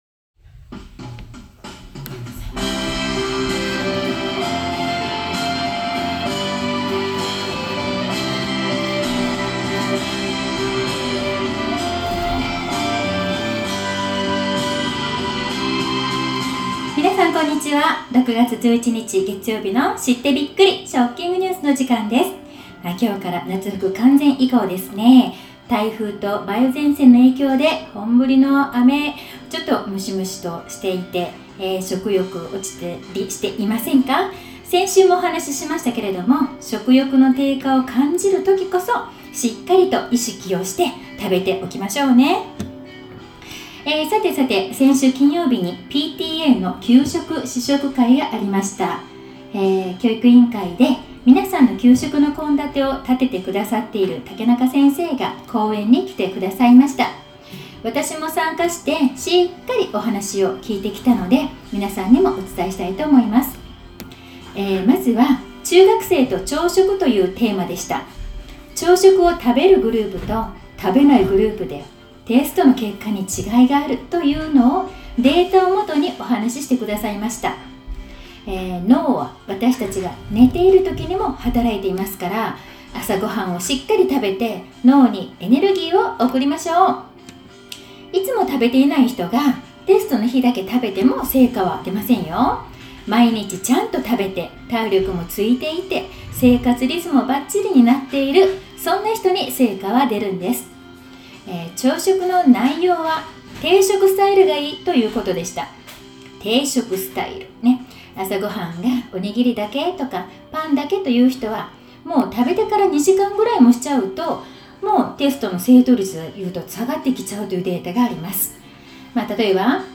食育放送６月１１日分 また，今日，食ing NEWS No.4を発行しました。